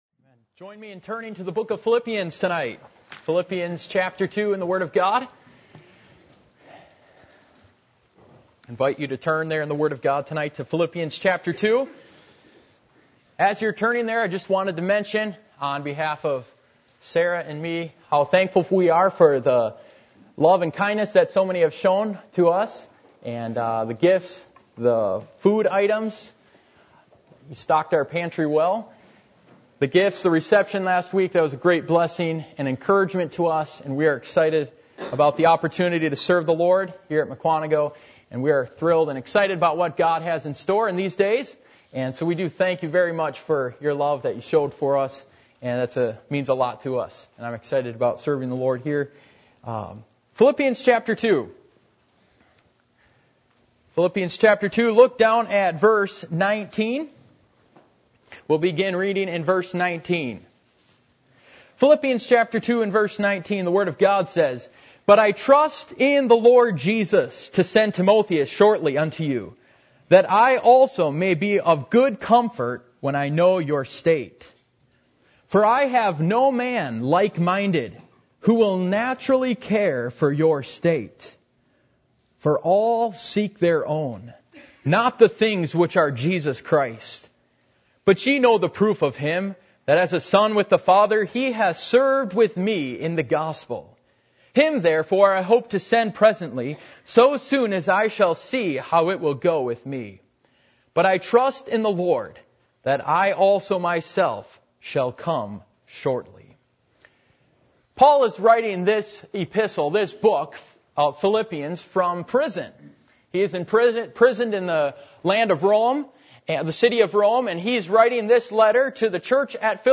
Passage: Philippians 2:19-23 Service Type: Sunday Evening